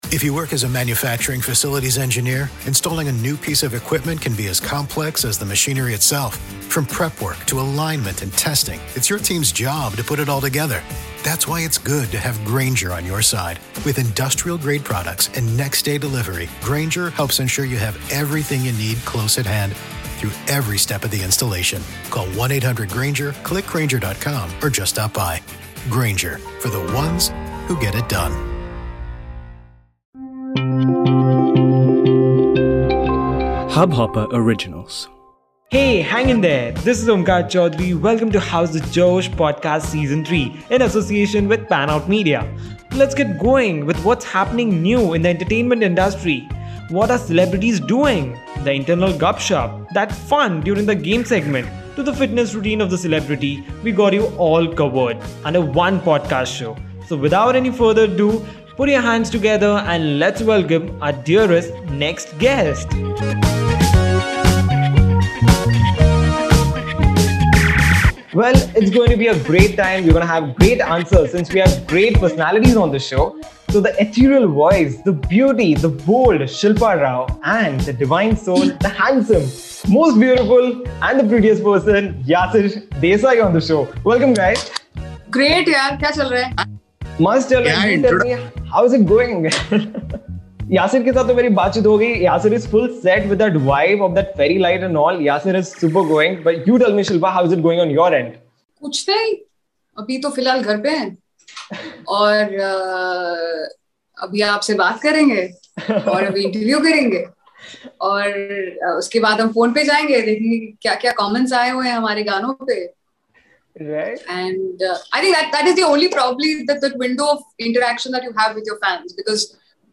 Headliner Embed Embed code See more options Share Facebook X Subscribe Shilpa is know for her Ethereal voice and Yasser for his Divine!